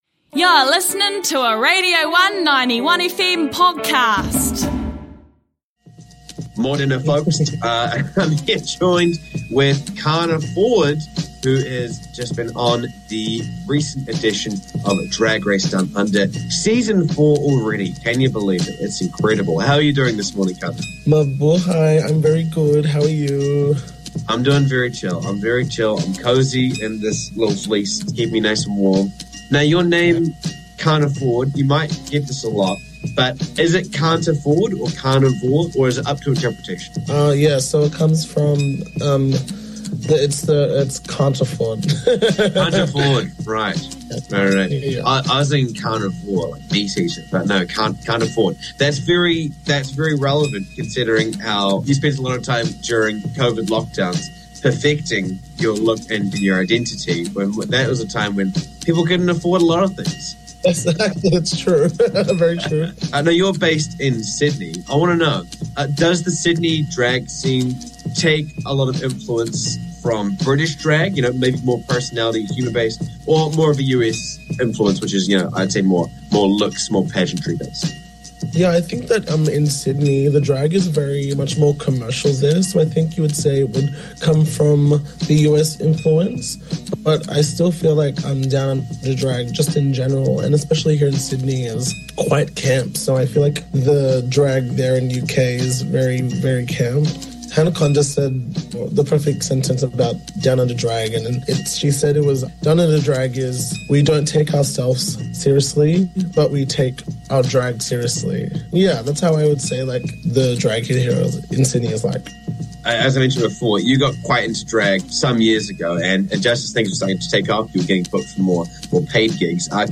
1 INTERVIEW